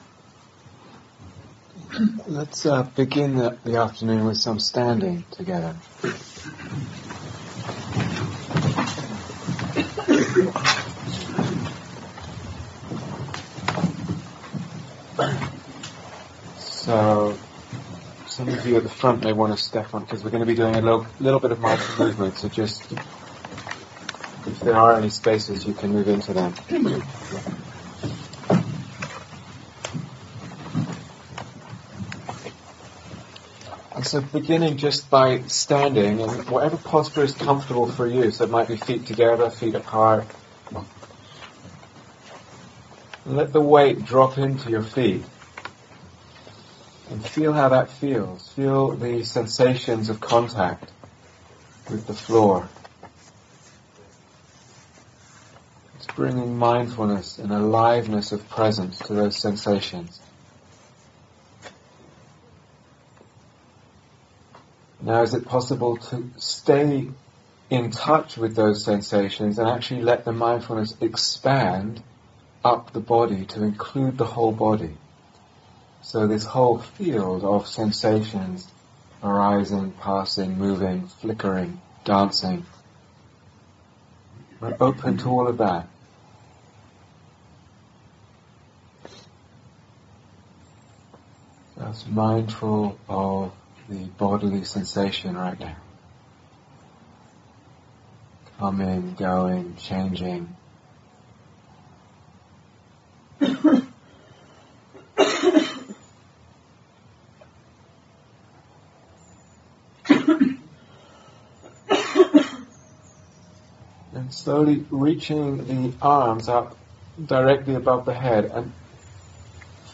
Standing Meditation
Retreat/SeriesDay Retreat, London Insight 2013